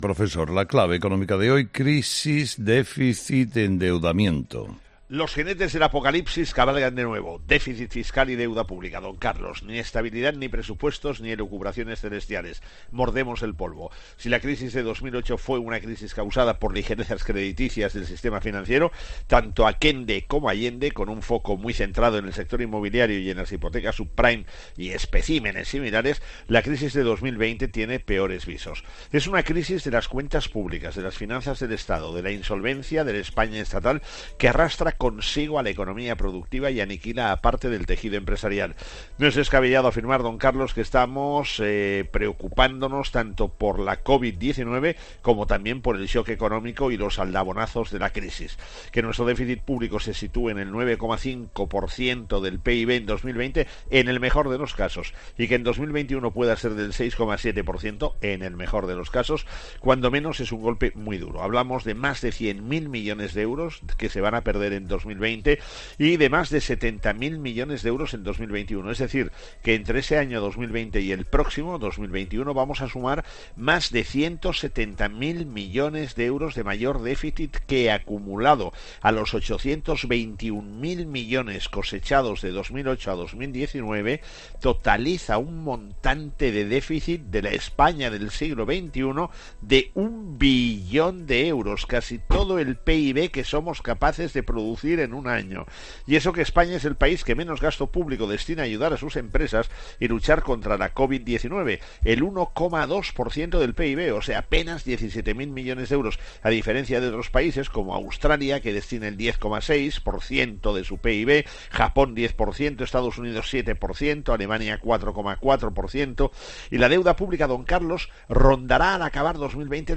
El profesor José María Gay de Liébana analiza en ‘Herrera en COPE’ las claves económicas del día.